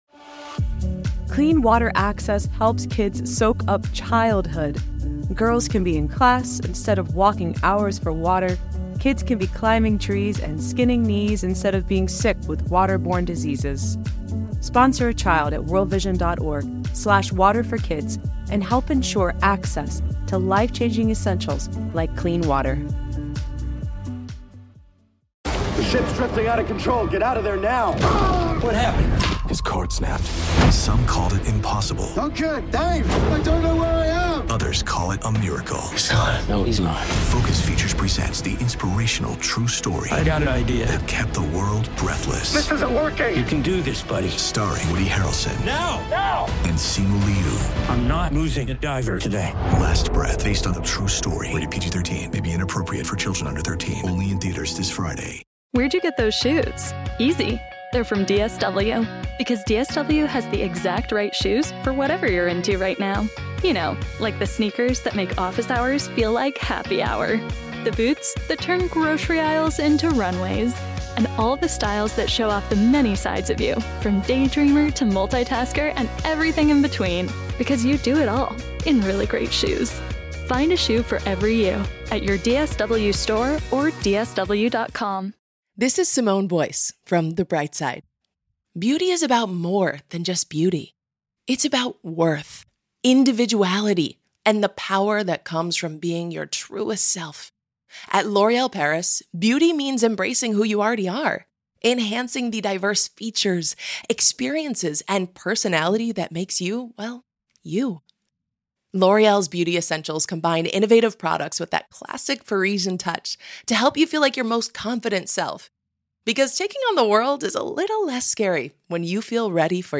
audio courtesy of the U.S. National Archives.